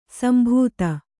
♪ sambhūta